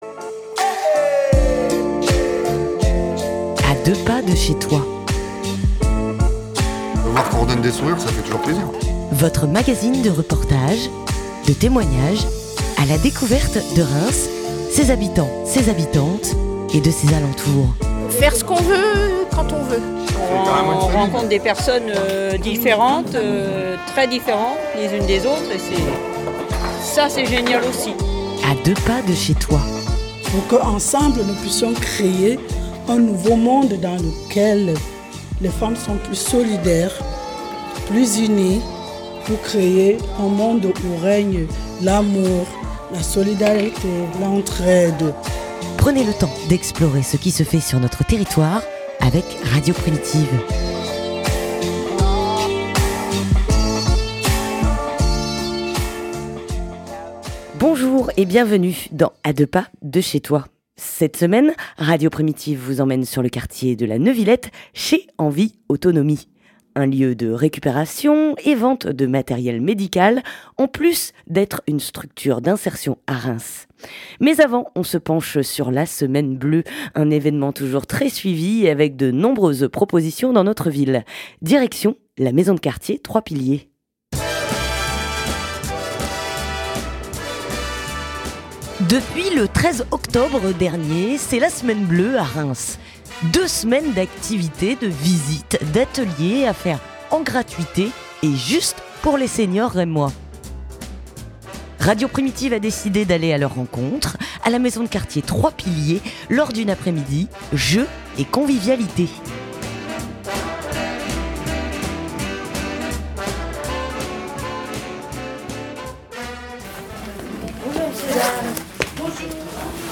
Radio Primitive a décidé d’aller à leur rencontre à la Maison de Quartier Trois Piliers lors d’un après midi jeu et convivialité.